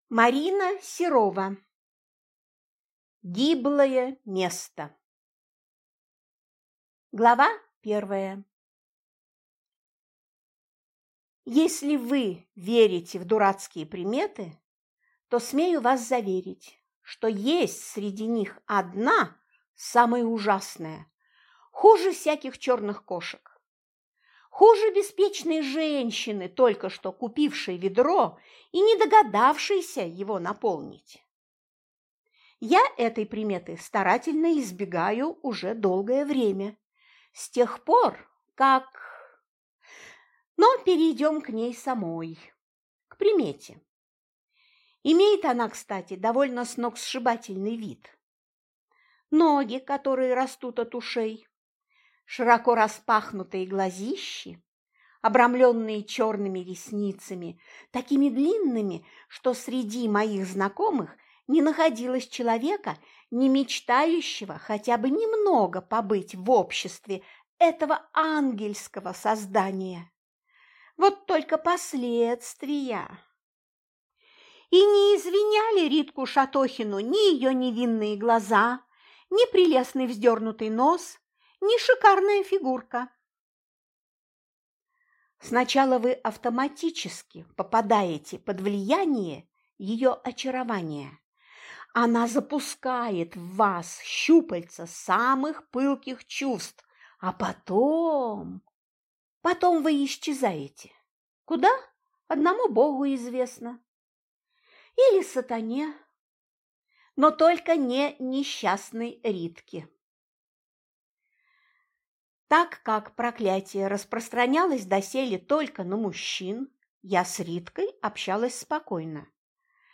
Аудиокнига Гиблое место | Библиотека аудиокниг